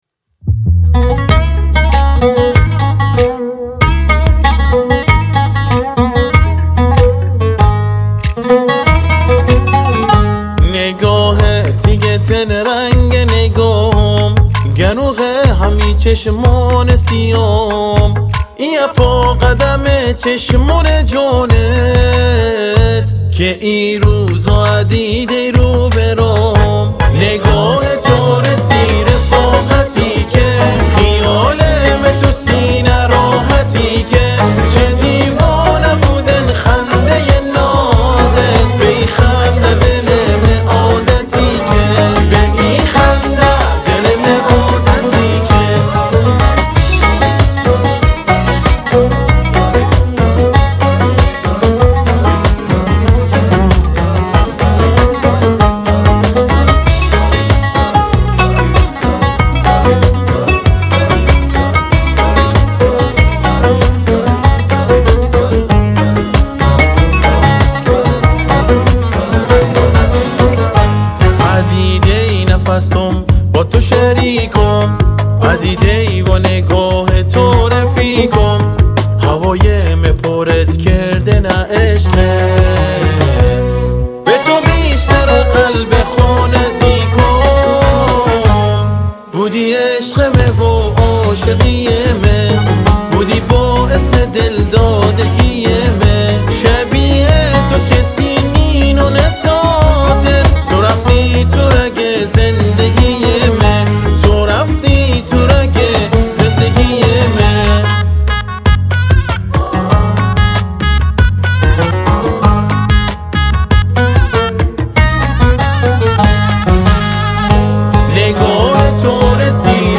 اثری شاد با صدای
عود
🎸 گیتار باس
پرکاشن
بک وکال